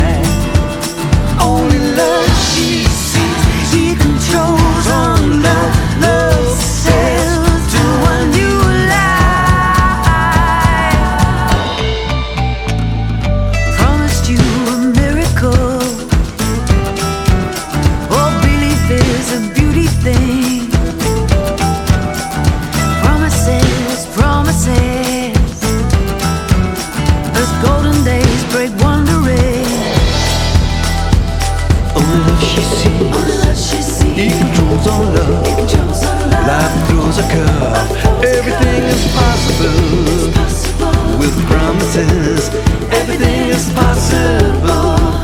L'invité célèbre est dans les choeurs.